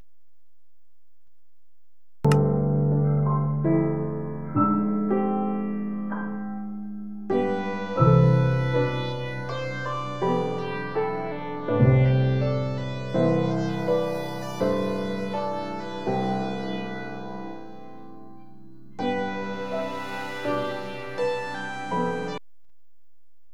In this sound, you can hear two songs running at one time.